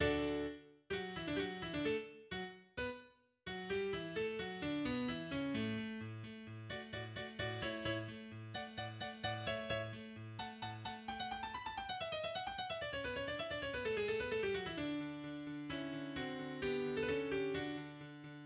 1er mouvement : Allegro
En ré majeur, ses sonates commencent en principe par un accord arpégé et huit doubles croches qui montent vers un sommet (sol fa mi ré sol fa mi ré la). Ensuite apparaissent des octaves brisées, en croches, à la main gauche, pendant que la main droite effectue une mélodie à tendance montante, en doubles notes, qui mène à deux mesures de doubles croches.